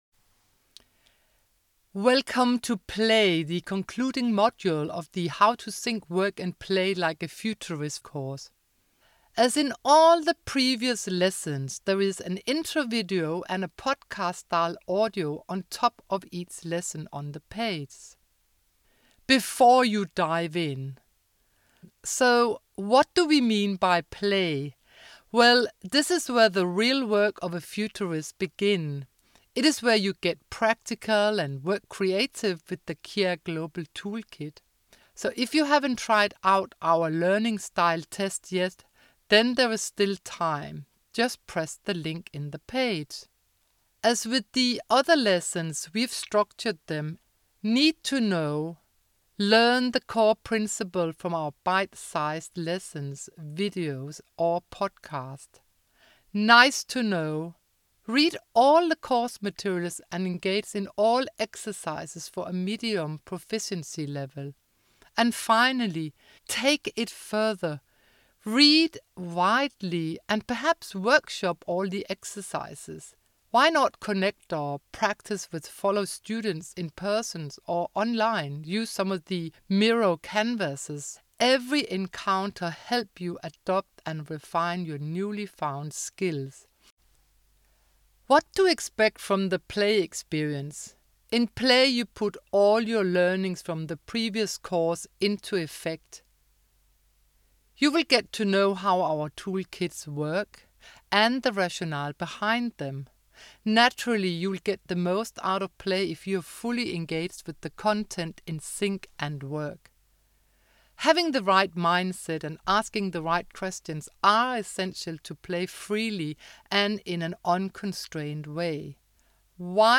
As in all the previous lessons, there is an intro video and a podcast style audio on top of each page.